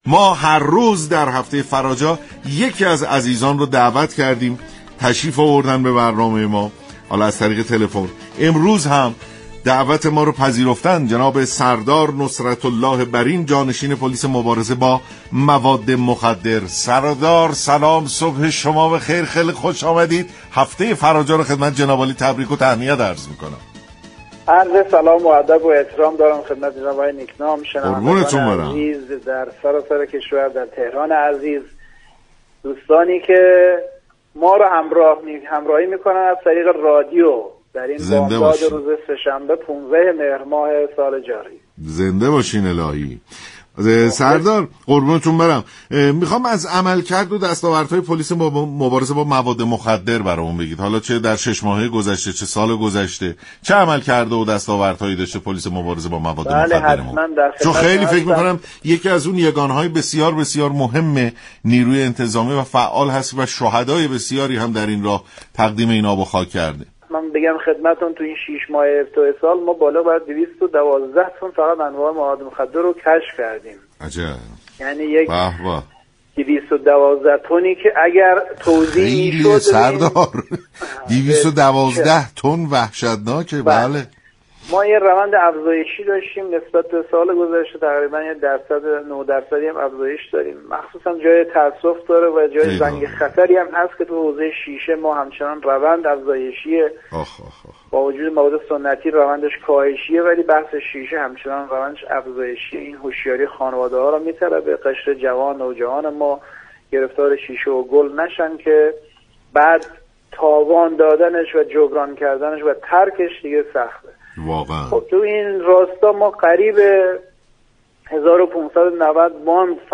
به گزارش فضای مجازی رادیو ایران؛ سردار نصرت‌الله برین جانشین پلیس مبارزه با مواد مخدر در برنامه سلام‌صبح‌بخیر به شرح دستاوردهای پلیس در مبارزه با مواد مخدر پرداخت و گفت: پلیس در شش ماهه اخیر بالغ بر 212 تن مواد مخدر را كشف كرده كه این میزان نسبت به سال گذشته 9 درصد افزایش داشته است.